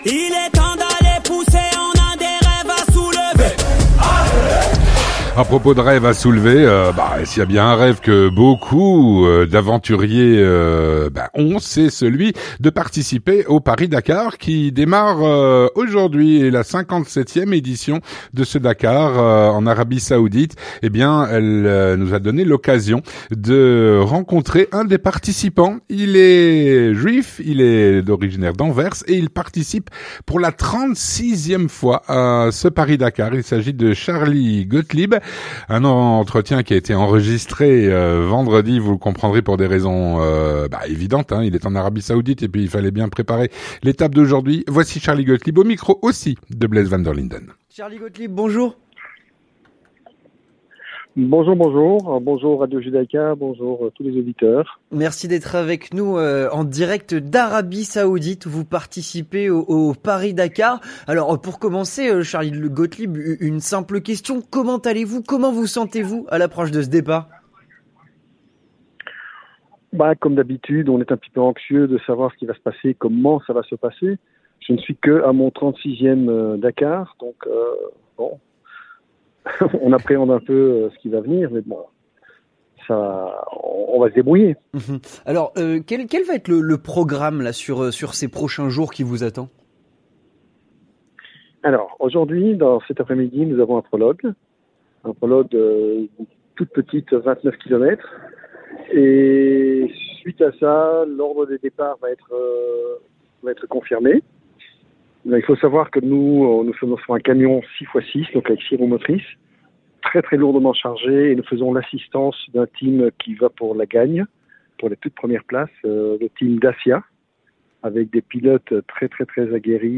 Un entretien enregistré vendredi